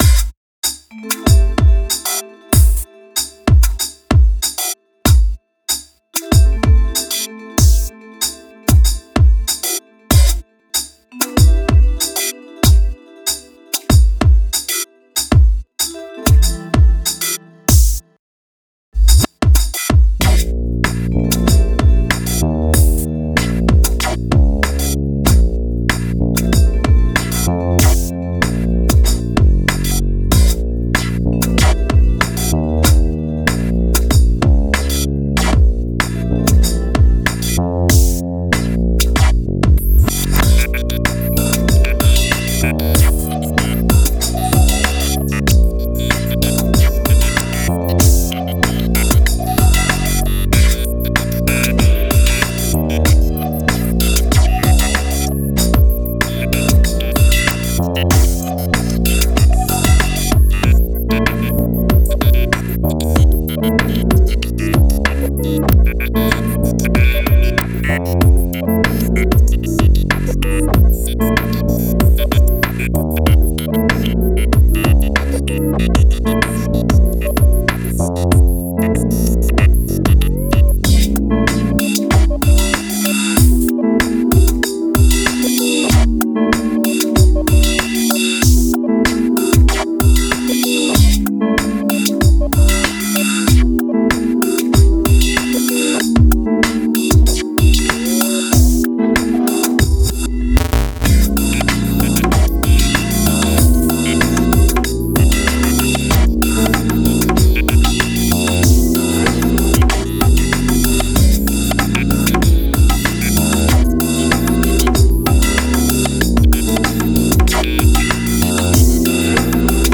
Excellent and extremely elegant electronic music.»